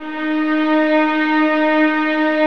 VIOLINS FN-R.wav